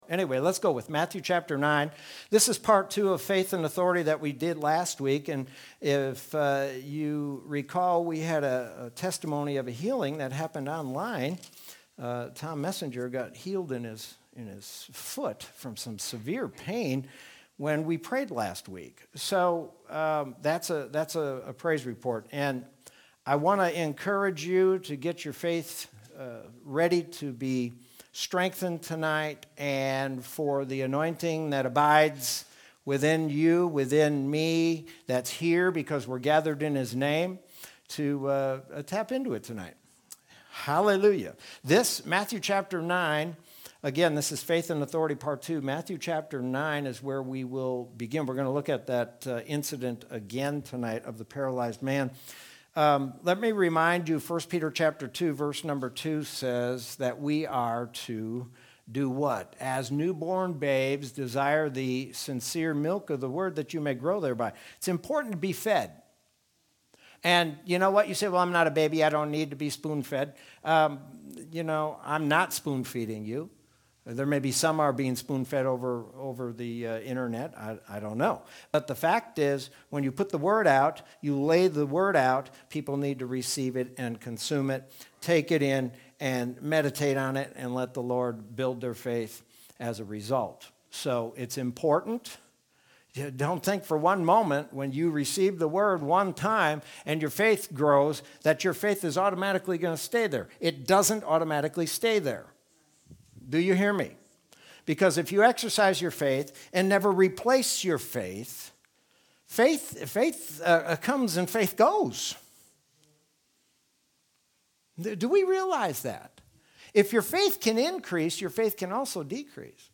Sermon from Wednesday, January 13th, 2021.